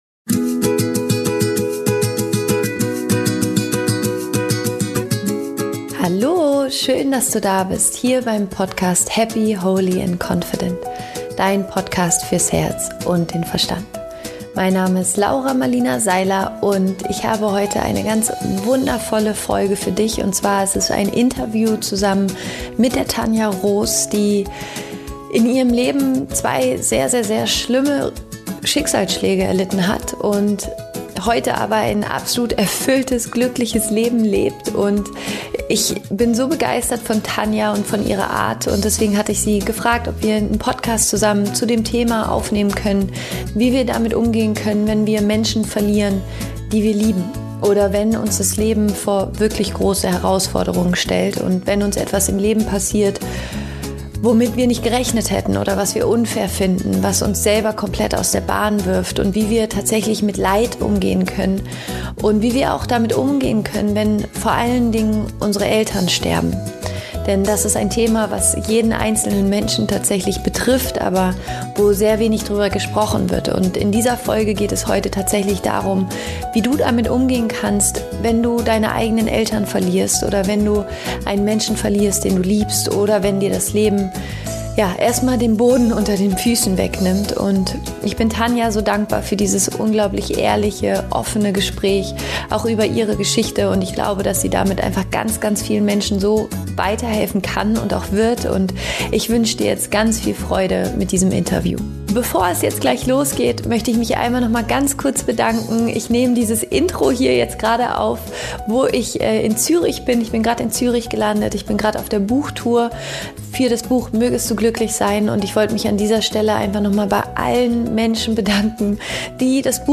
Wie du den Verlust von geliebten Menschen verarbeiten kannst - Interview